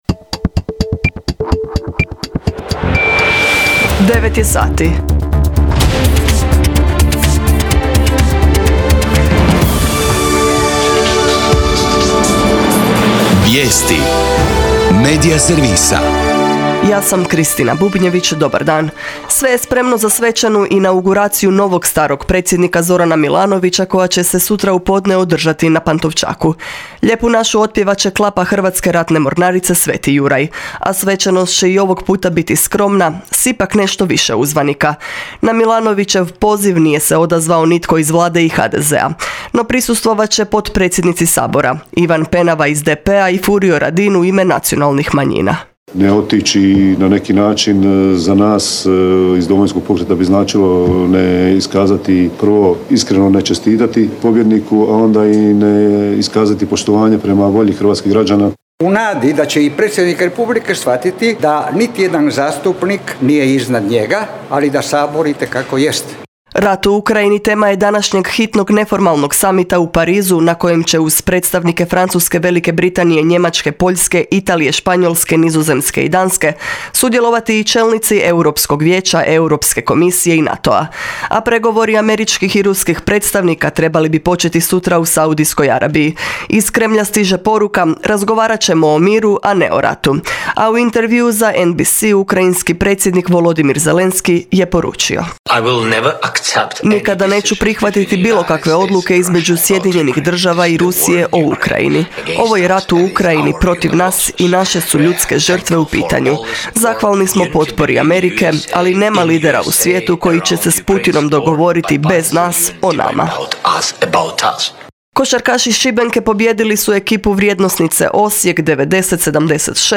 VIJESTI U 9